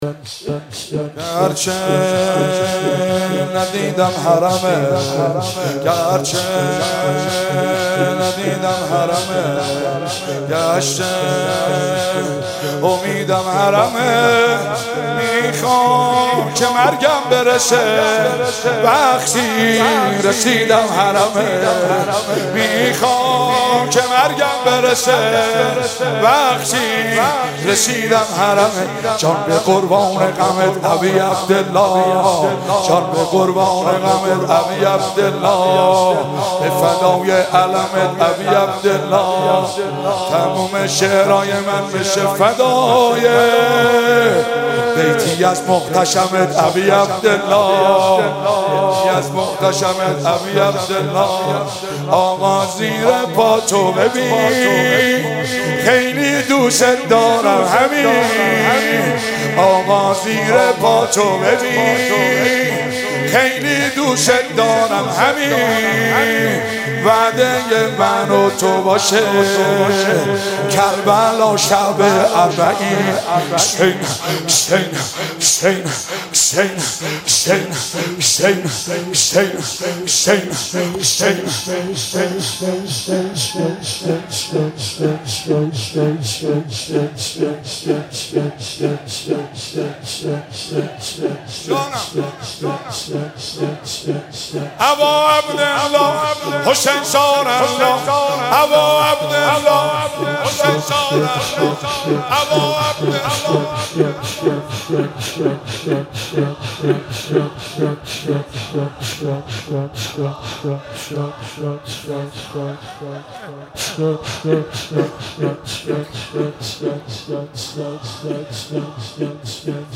شور شب نهم محرم
محرم۱۳۹۷هیئت فاطمیون قم